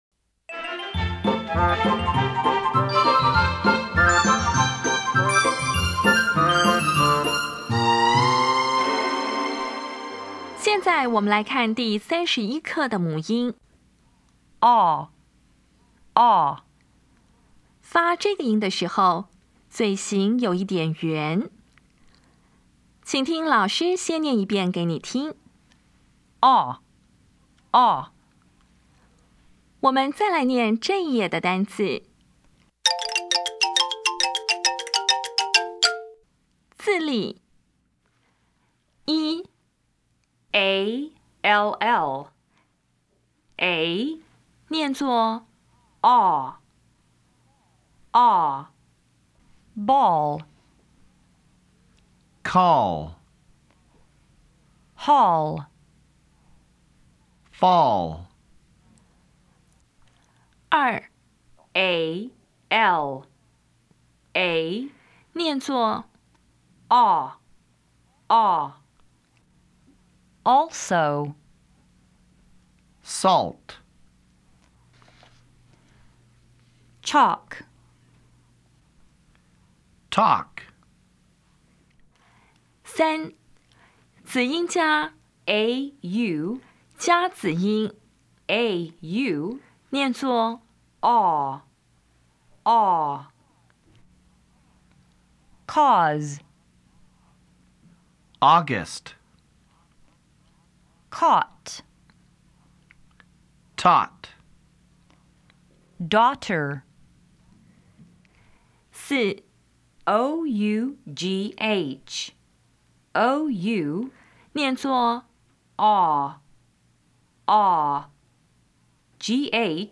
当前位置：Home 英语教材 KK 音标发音 母音部分-2: 长母音 [ɔ]
[ɔ]
音标讲解第三十一课
[bɔl]
Listening Test 14